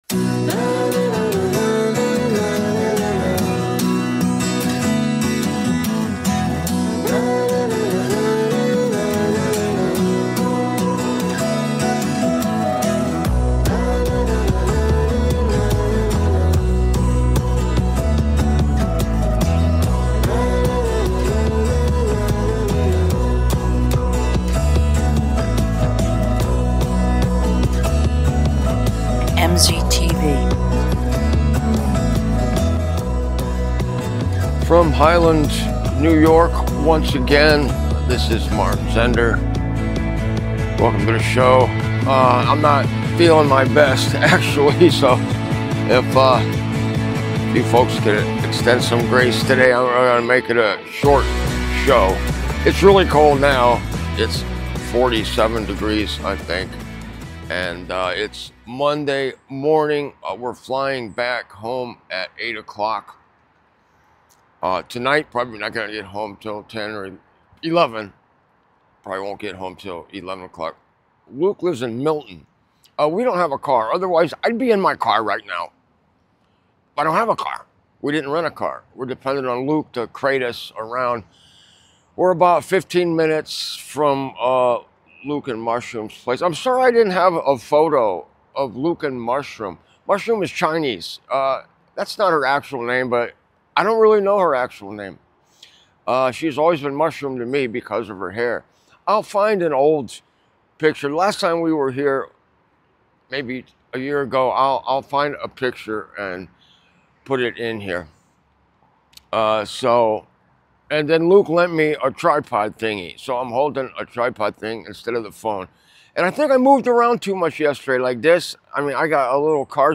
Last broadcast from Highland, NY.